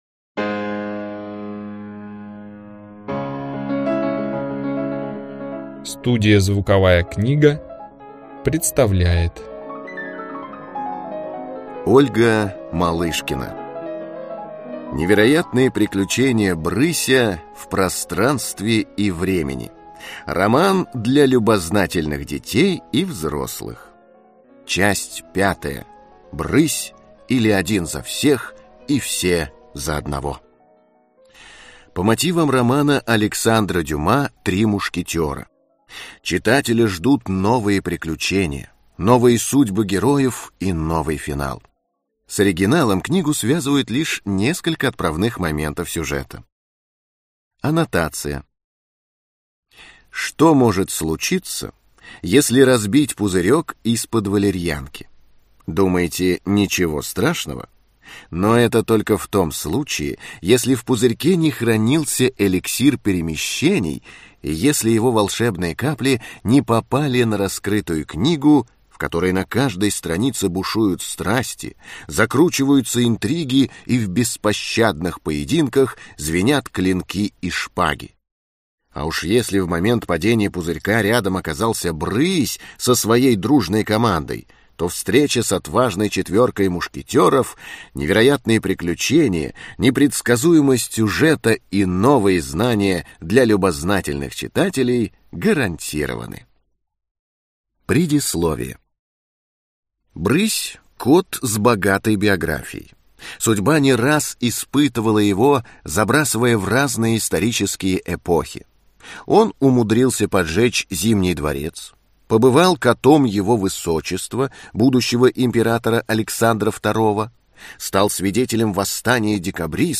Аудиокнига Книга 5. Брысь, или один за всех и все за одного | Библиотека аудиокниг